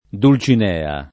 dul©in$a; sp. dulTin%a] pers. f. — personaggio di M. de Cervantes — sempre con pn. it., e anche con d‑ minusc., come nome comune d’uso scherz.: la sua D. o la sua d., «la sua bella»